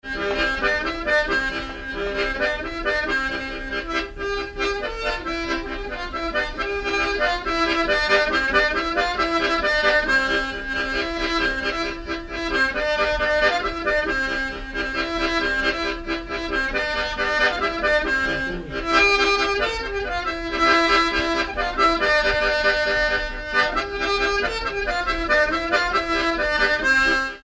Bellevaux
Pièce musicale inédite